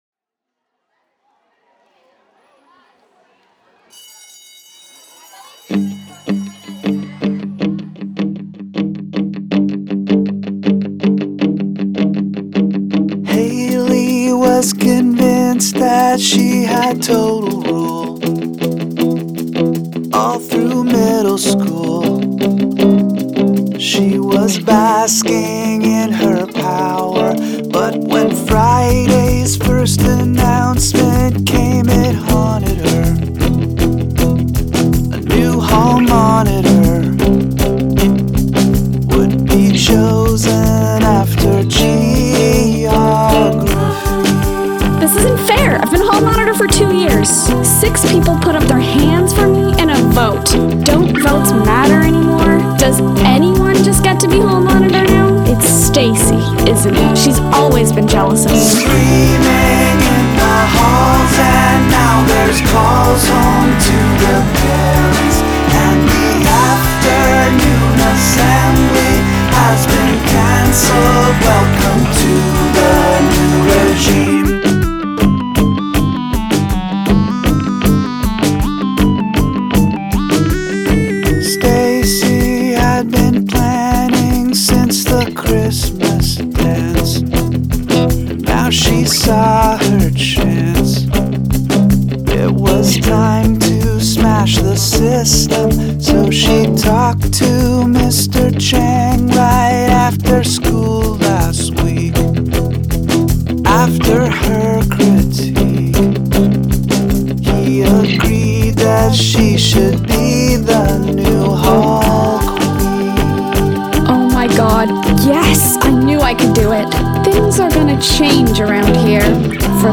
a tale of middle school drama put into a beautiful pop song.